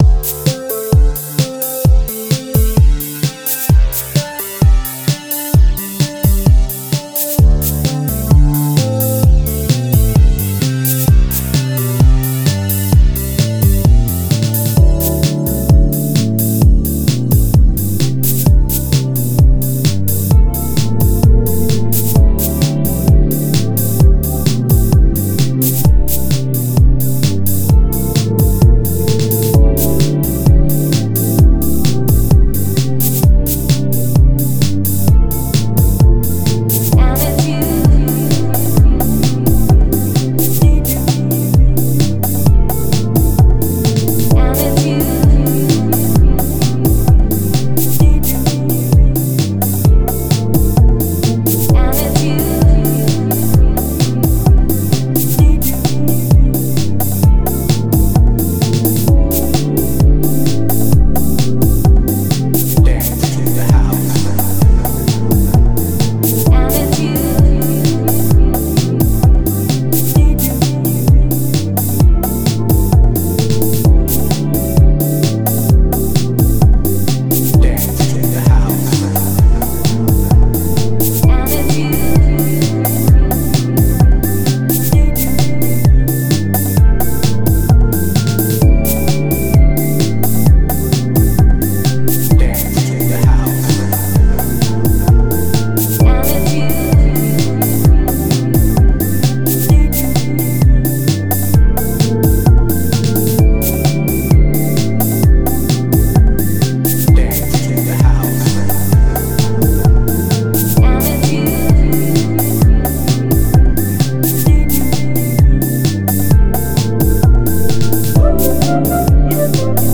Genre House